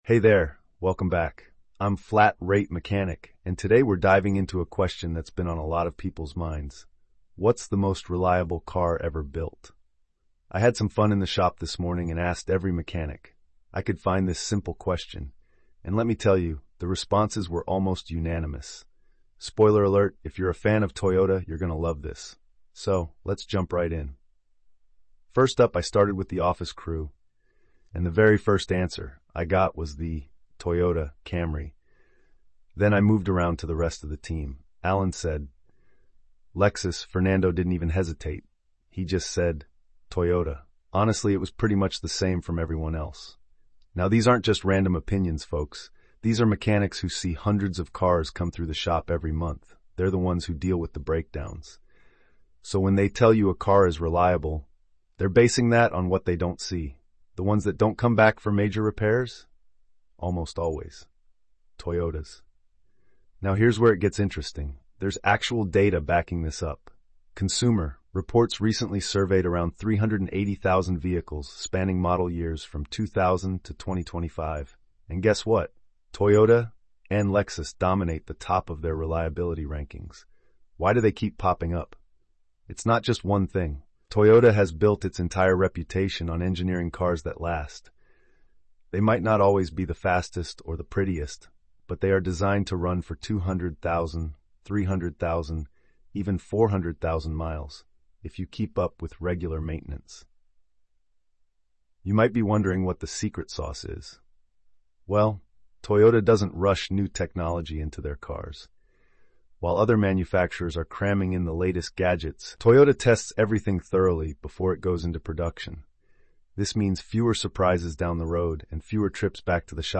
Alright guys, so this morning we had a little fun in the shop. I walked around with my camera and asked every single person one simple question: what's the most reliable car ever built?